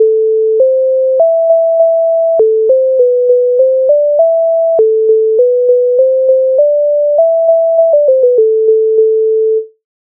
MIDI файл завантажено в тональності C-dur
Чом чом не прийшов Українська народна пісня